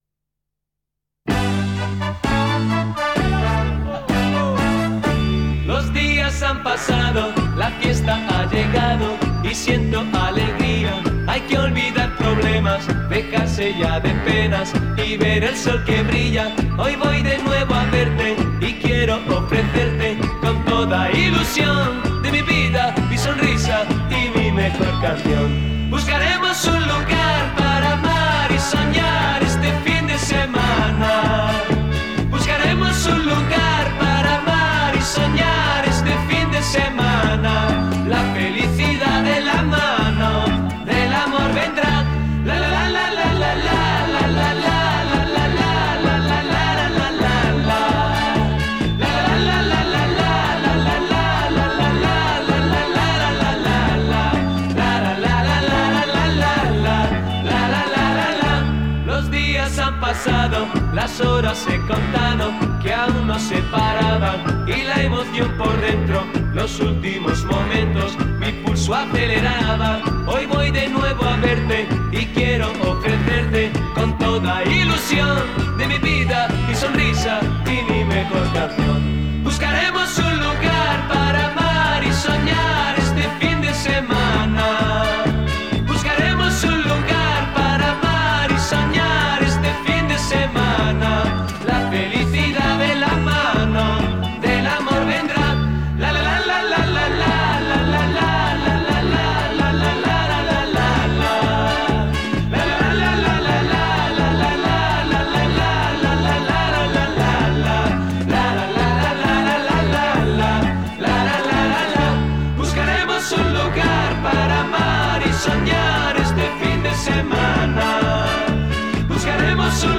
canciones veraniegas